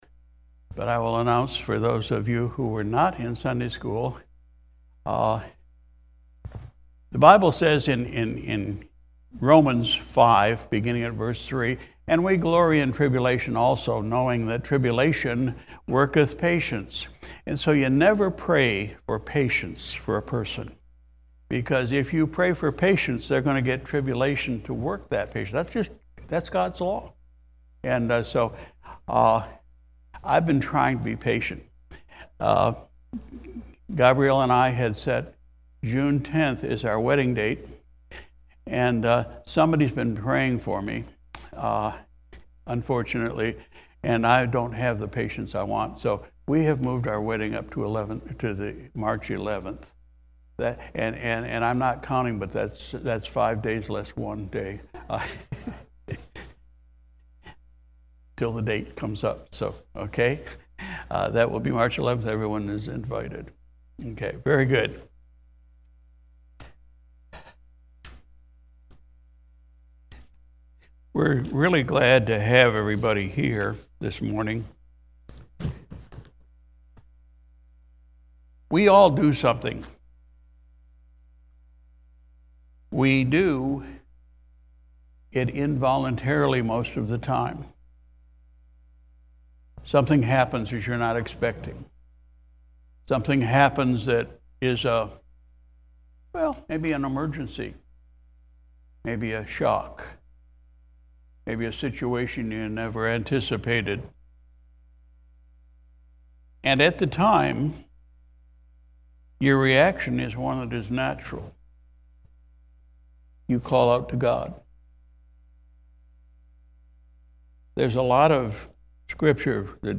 February 5, 2023 Sunday Morning Service Pastor’s Message: “Calling Out to God”